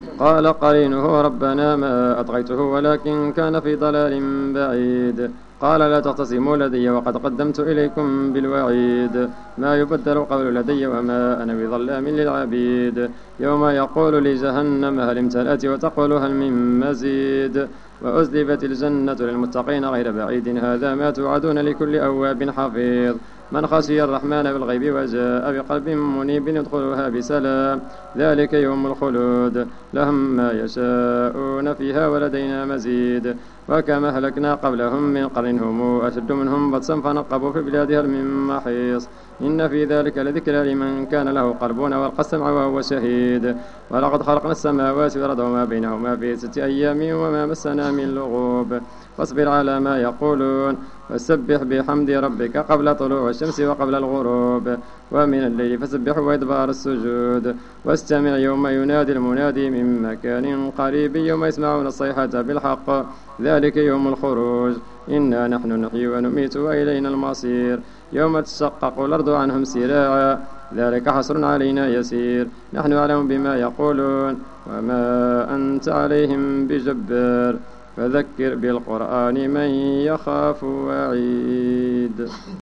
صلاة التراويح ليوم 25 رمضان 1431 بمسجد ابي بكر الصديق ف الزو
صلاة رقم 08 ليوم 25 رمضان 1431 الموافق سبتمبر 2010